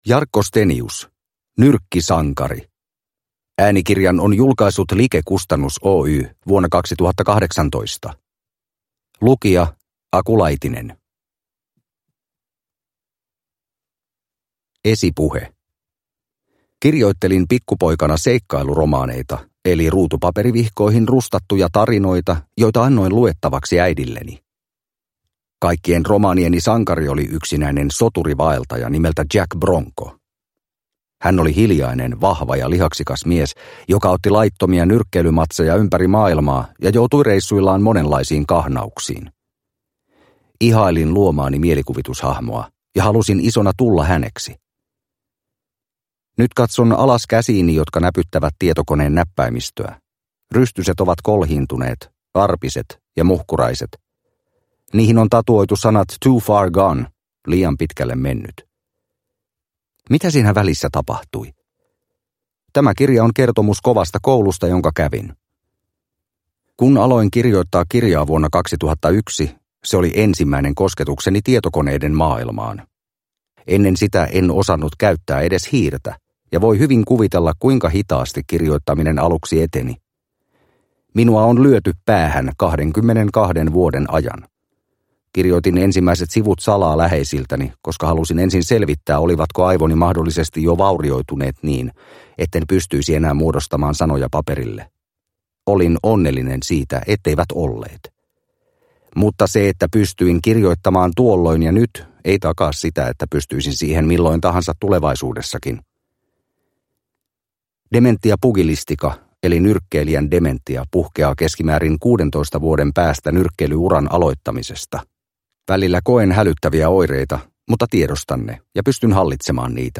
Nyrkkisankari – Ljudbok – Laddas ner